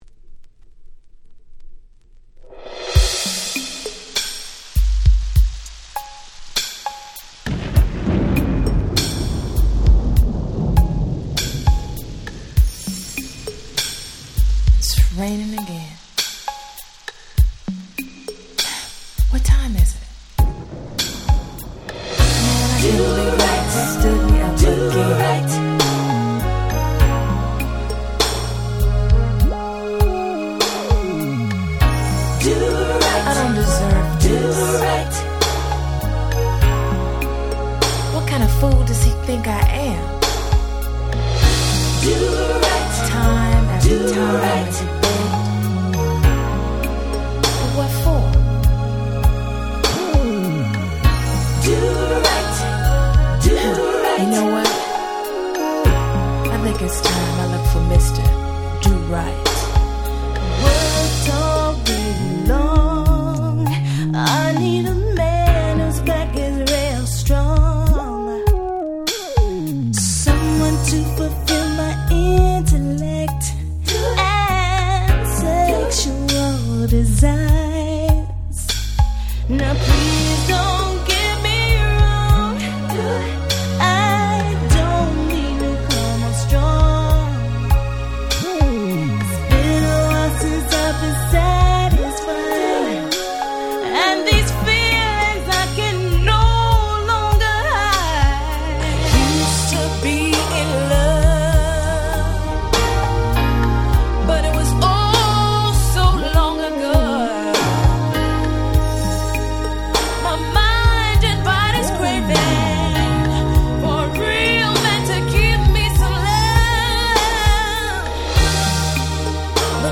92' Nice R&B / Slow Jam !!
90's スロウジャム